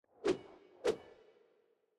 sword 2.wav